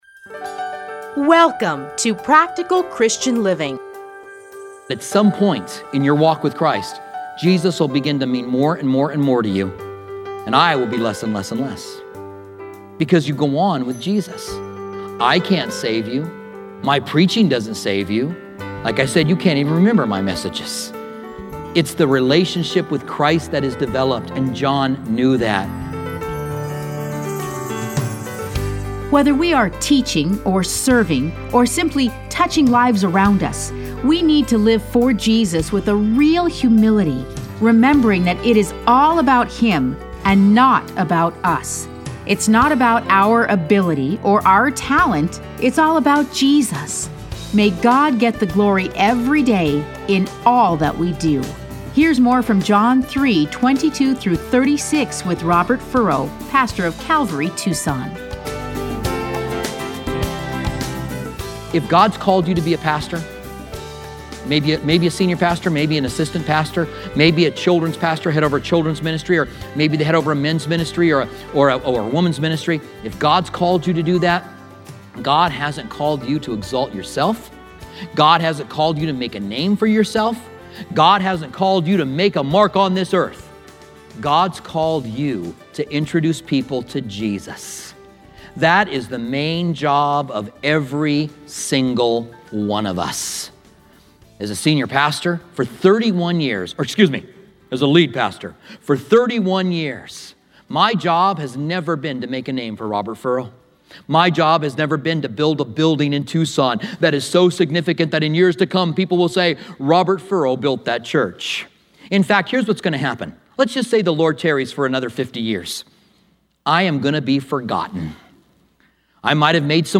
Listen to a teaching from John 3:22-36.